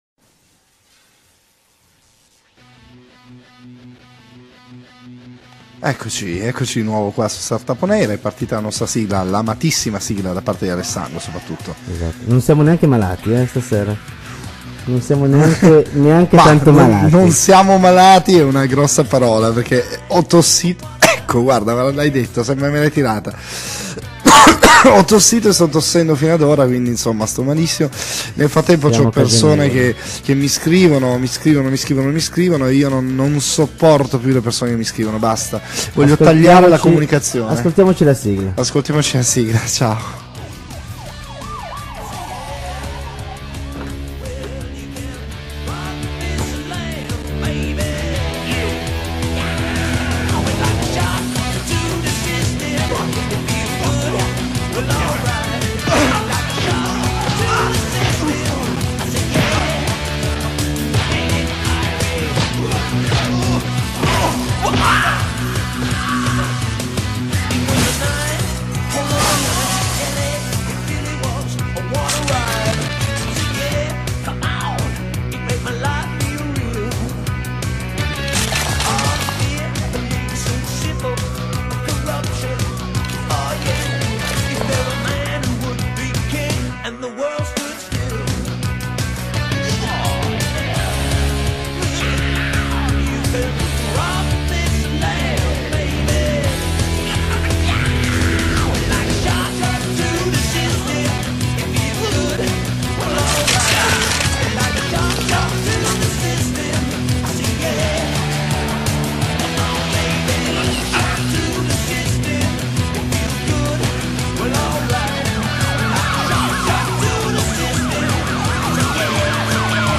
StartupOnAir Quindicesima puntata – Intervista